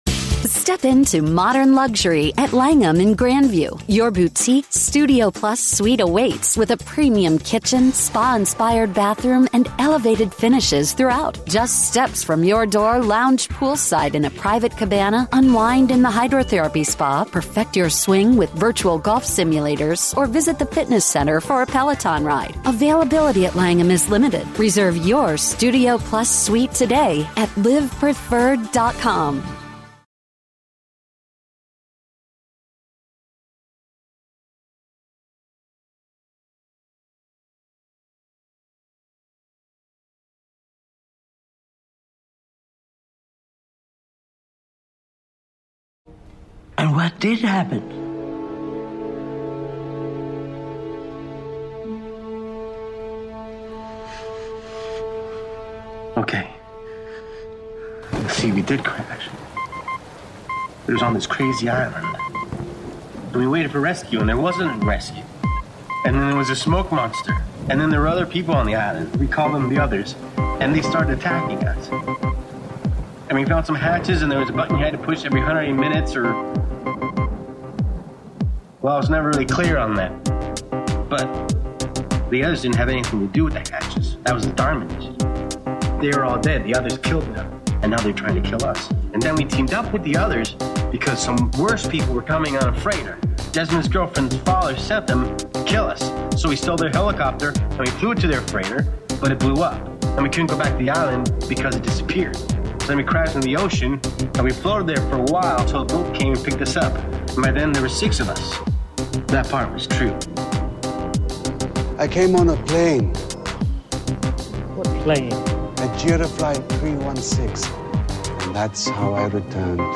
This week's INTERVIEW is with returning guest, Rolling Stone's Chief TV Critic, Alan Sepinwall!